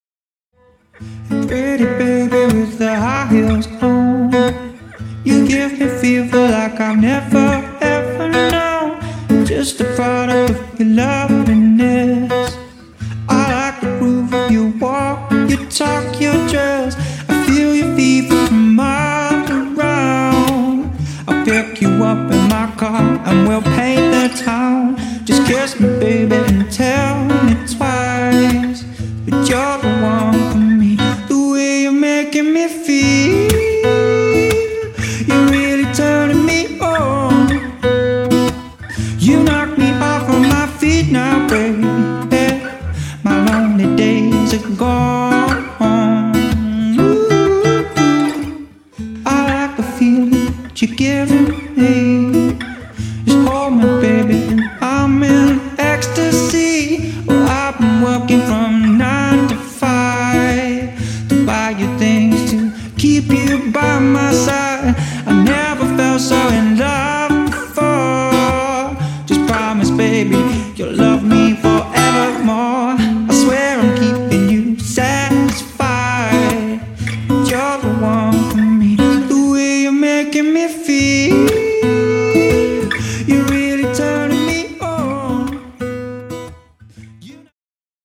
• Solo Acoustic
Male Vocals / Guitar